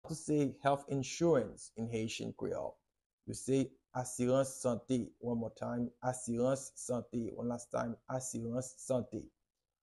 How to say “Health Insurance” in Haitian Creole - “Asirans Sante” pronunciation by a native Haitian Teacher
“Asirans Sante” Pronunciation in Haitian Creole by a native Haitian can be heard in the audio here or in the video below:
How-to-say-Health-Insurance-in-Haitian-Creole-Asirans-Sante-pronunciation-by-a-native-Haitian-Teacher.mp3